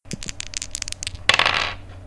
diceRoll.ogg